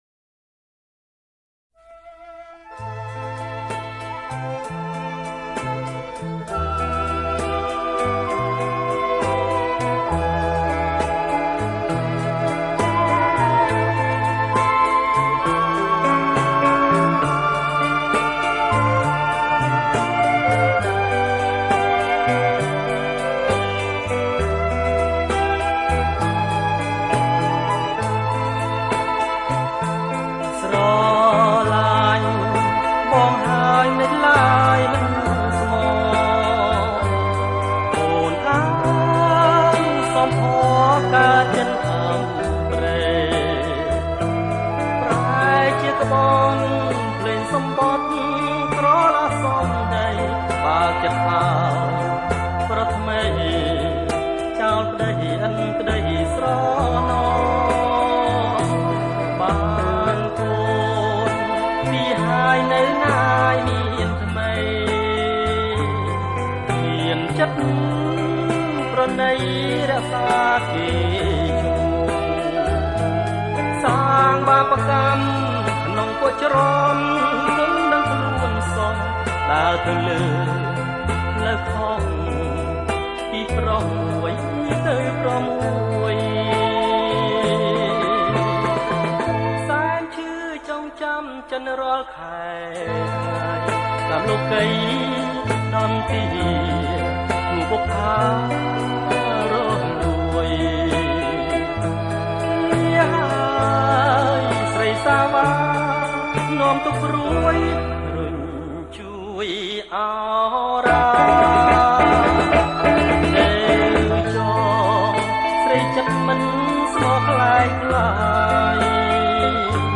• ប្រគំជាចង្វាក់ SLOW ROCK
ប្រគំជាសង្វាក់ Slow Rock